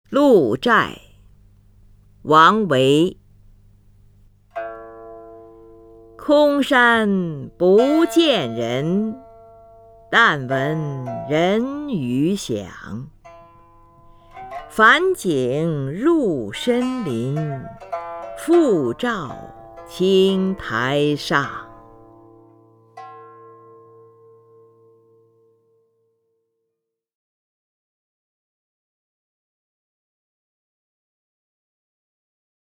林如朗诵：《鹿柴》(（唐）王维) （唐）王维 名家朗诵欣赏林如 语文PLUS